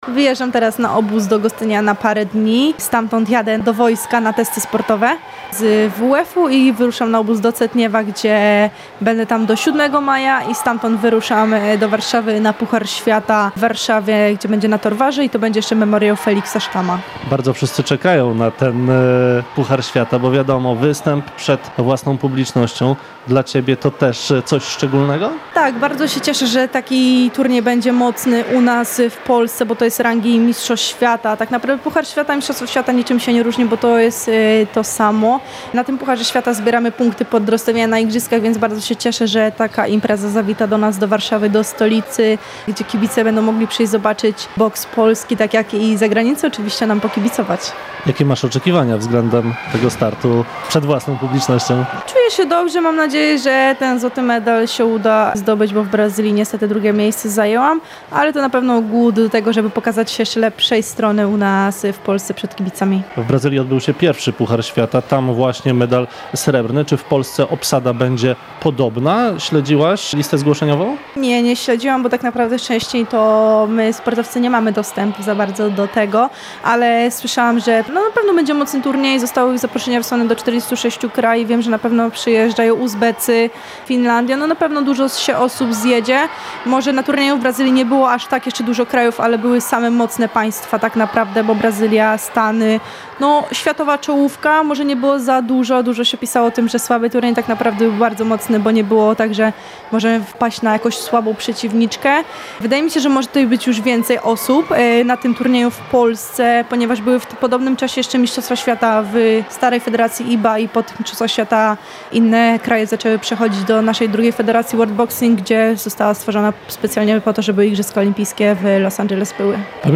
Rozmowa w materiale dźwiękowym: